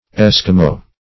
Eskimo \Es"ki*mo\, n.; pl.